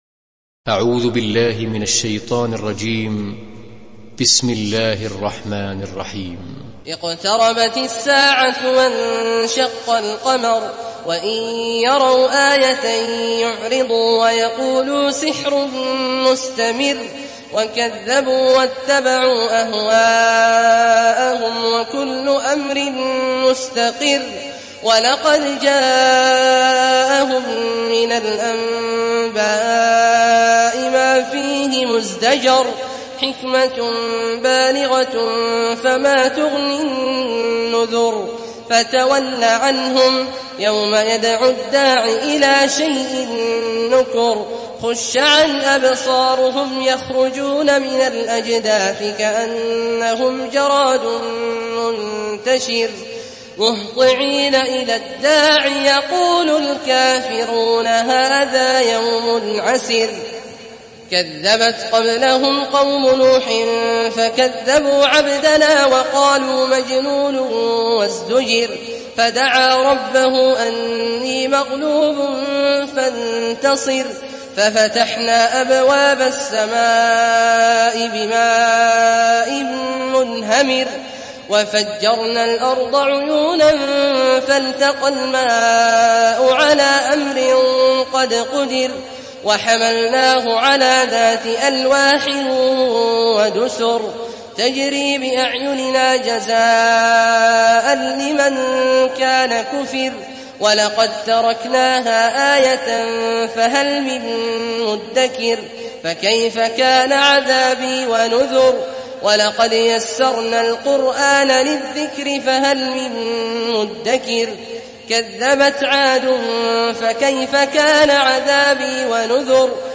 Surah Al-Qamar MP3 by Abdullah Al-Juhani in Hafs An Asim narration.
Murattal Hafs An Asim